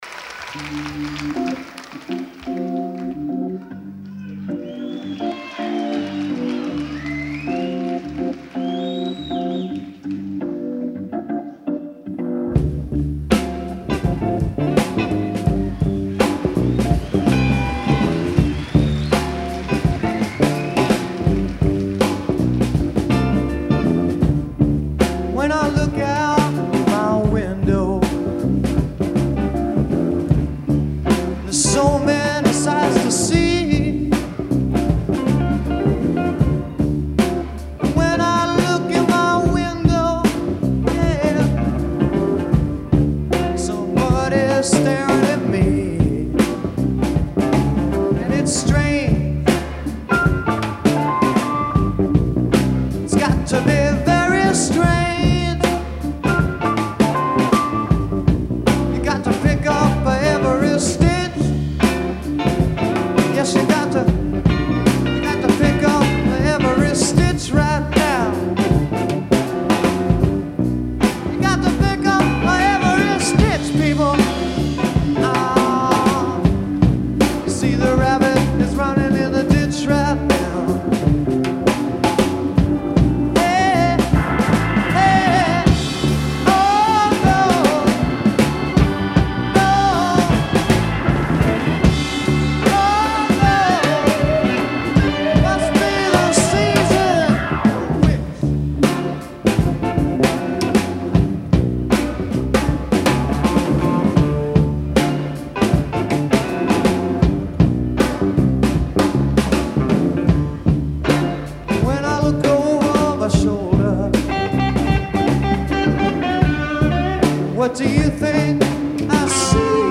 guitar
Live at the Fillmore